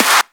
Clap
ED Claps 19.wav